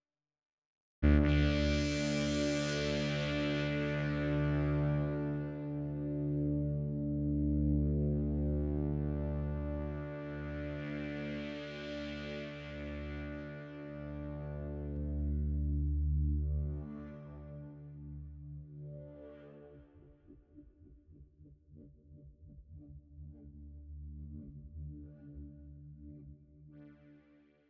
Down under Pad - Audionerdz Academy
Down-under-Pad-.wav